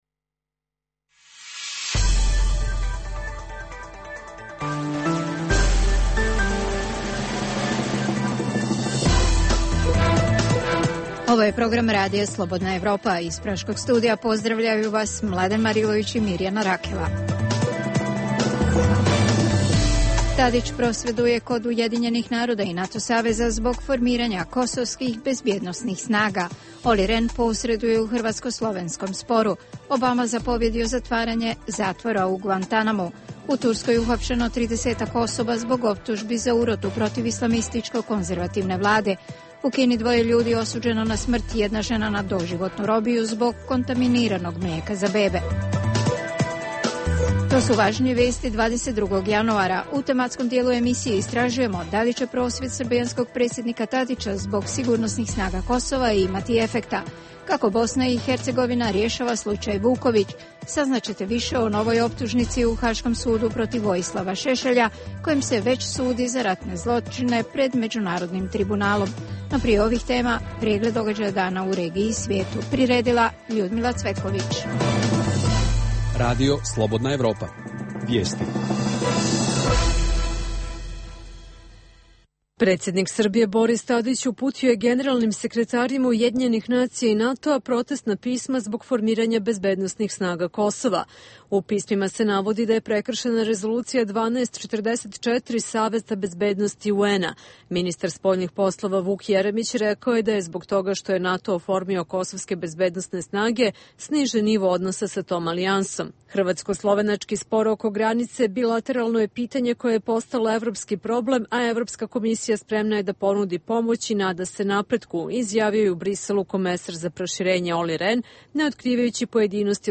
Možete čuti i razgovor sa književnikom Vladimirom Pištalom, dobitnikom nagrade "NIN"-a za roman " Tesla, portret među maskama".